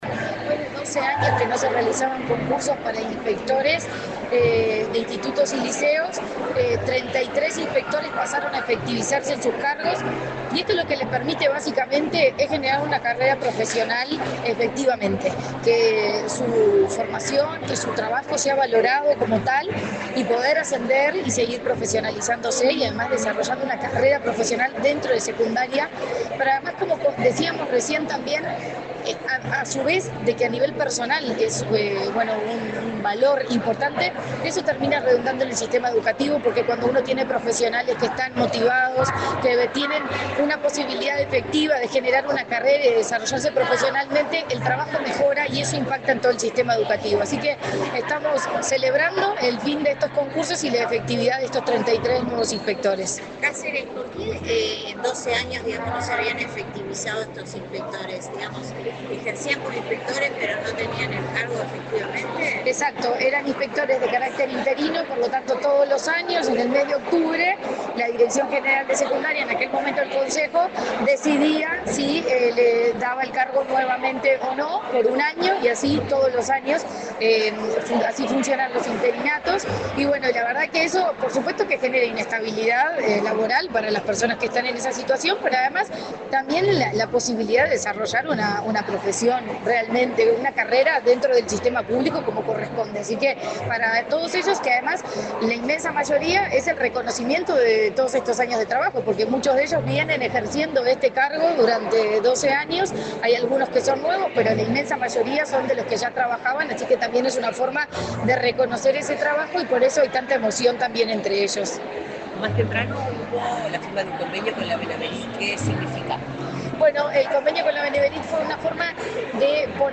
Declaraciones de la presidenta de la ANEP, Virginia Cáceres, en acto de Secundaria
La presidenta de la Administración Nacional de Educación Pública (ANEP), Virginia Cáceres, dialogó con la prensa, luego de participar en el acto de